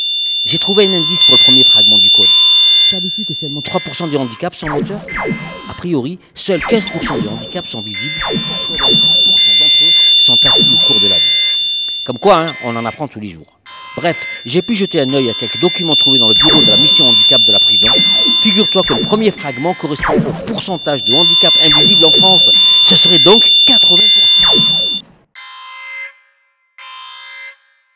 Des bourdonnements et des sifflements se manifestent par intermittence dans vos oreilles.
Malgré tout, il vous est difficile de comprendre ses propos avec ces satanés acouphènes.
Enregistrement-avec-accouphenes2.wav